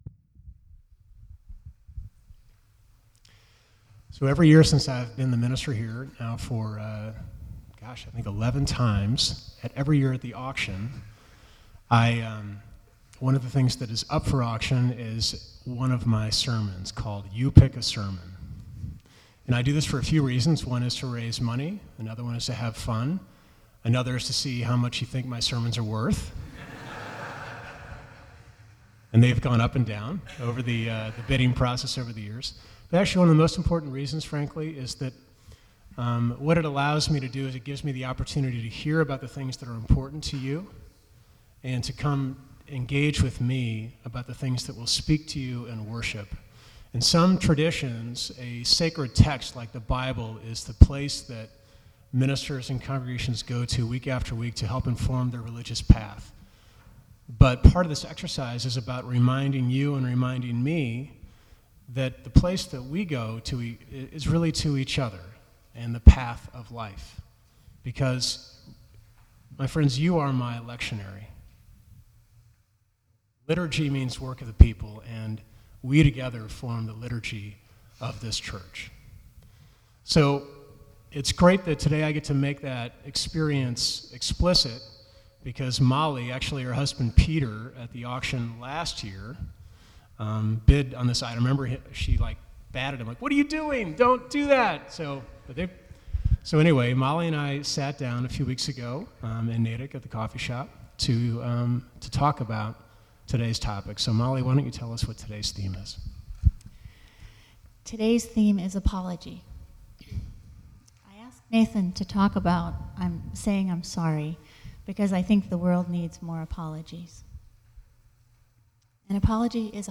From Series: "2014 Sermons"
Sermon3_30_14.mp3